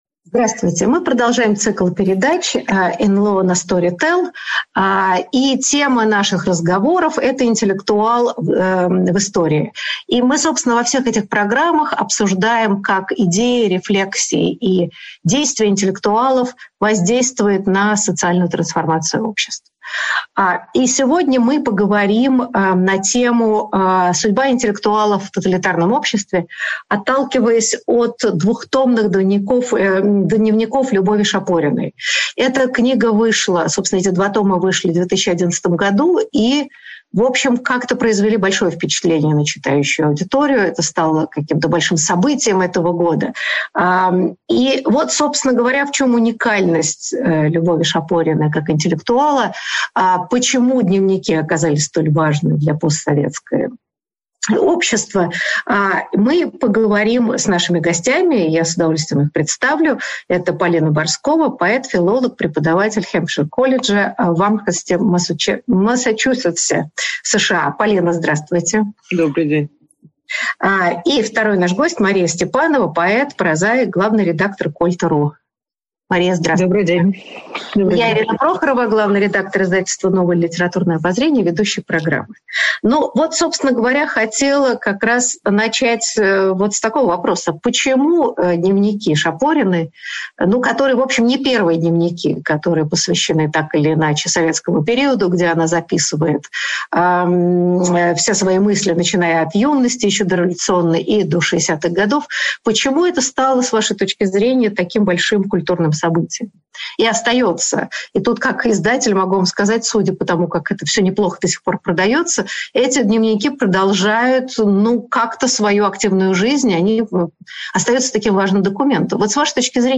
Аудиокнига Любовь Шапорина | Библиотека аудиокниг